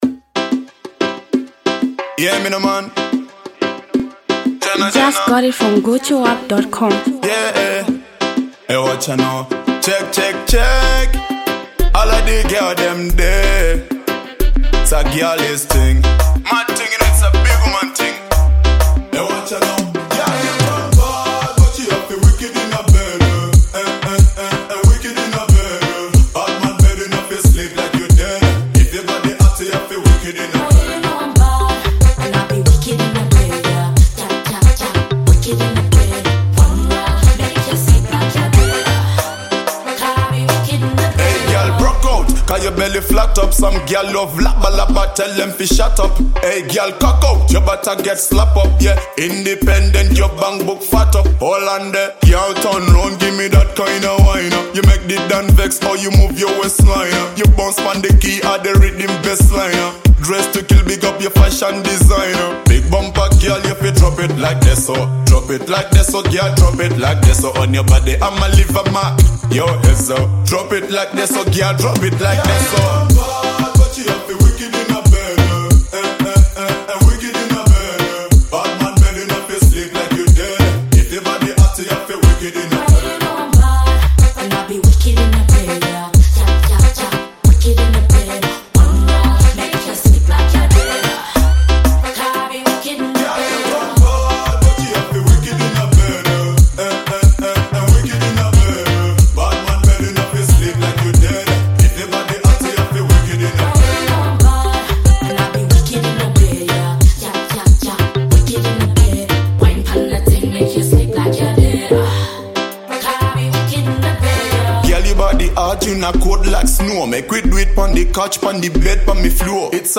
Zambian Mp3 Music
reggae dancehall